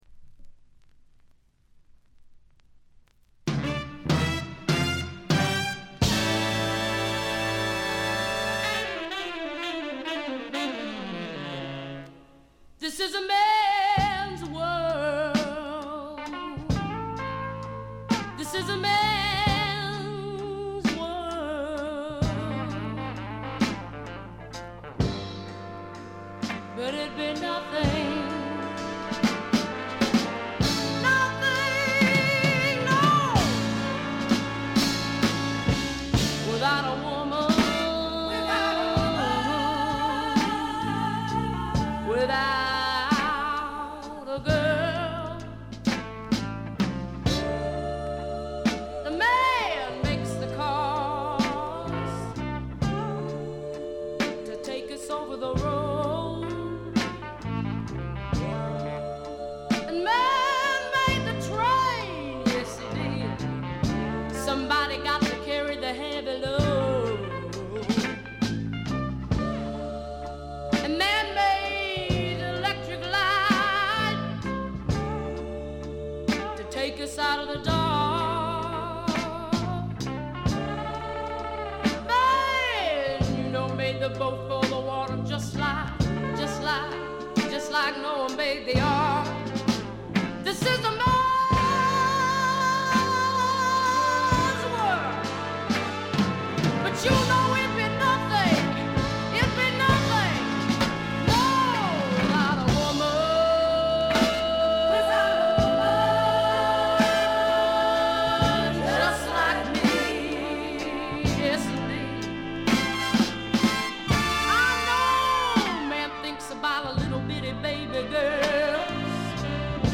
鑑賞を妨げるほどのノイズはありません。
試聴曲は現品からの取り込み音源です。